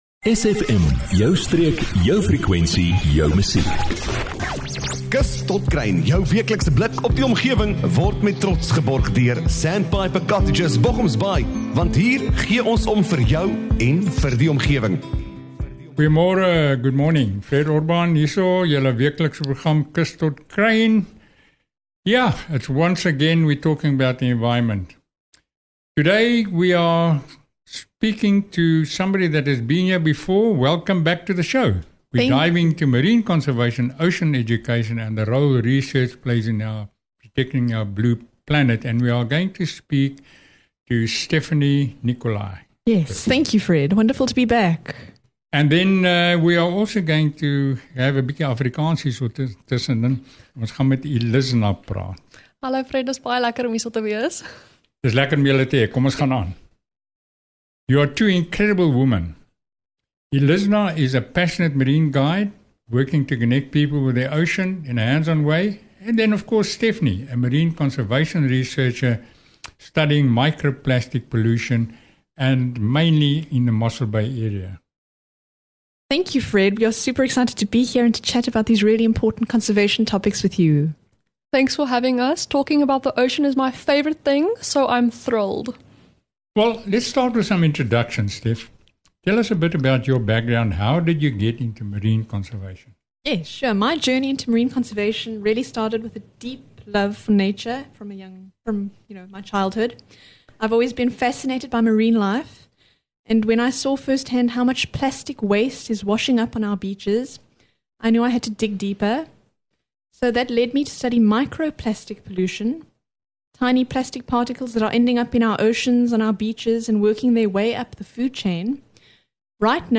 🎙 KUS TOT KRUIN – 👨‍🏫 EcoLearning/Omgewingslering 👩‍🏫 🎙 This is Part 2 of our eye-opening discussion on the devastation our oceans face through plastic pollution 🧴🐢.